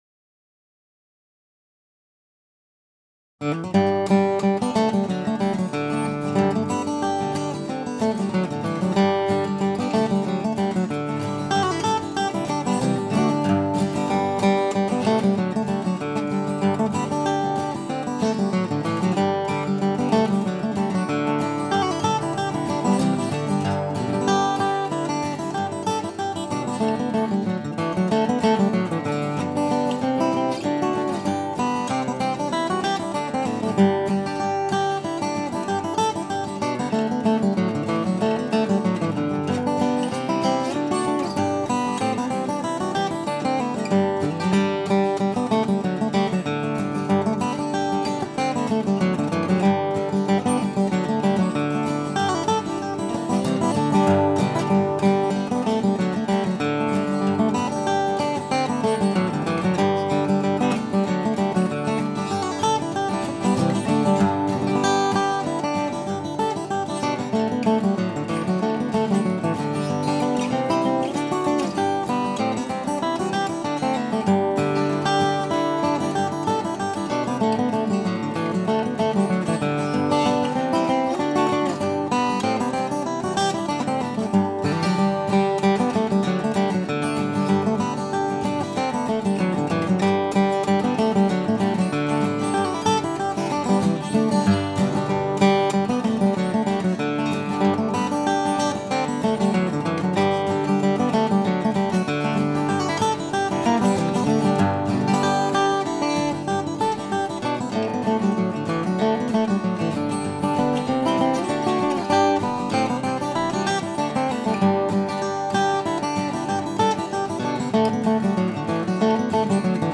12 fret 00 "Concert" size